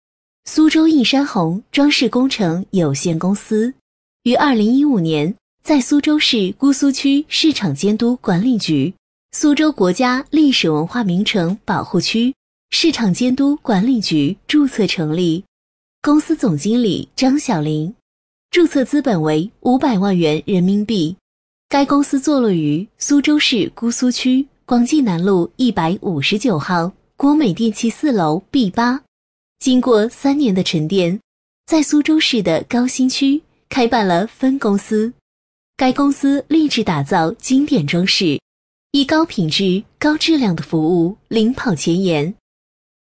【女50号专题】年轻-映山红装饰
【女50号专题】年轻-映山红装饰.mp3